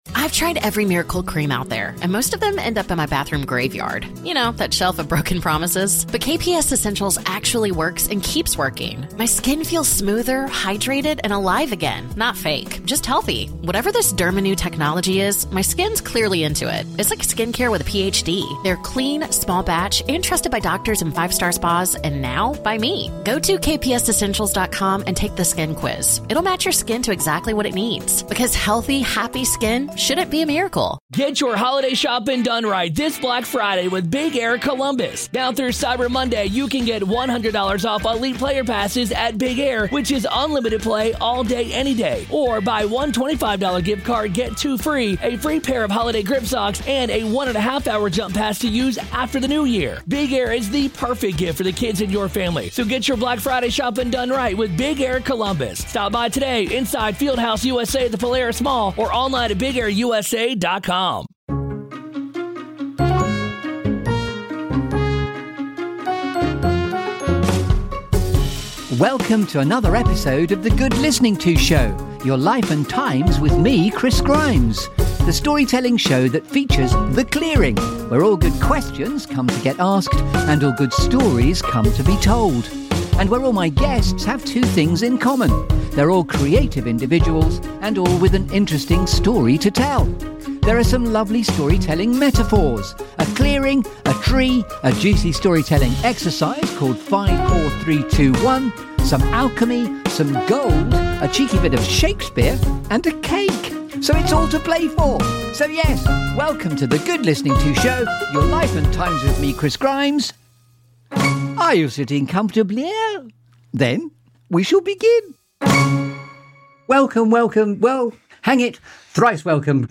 The Good Listening To Show is the Desert Island Discs of UKHR. This feel-good Storytelling Show that brings you ‘The Clearing’.
Think Stories rather than Music!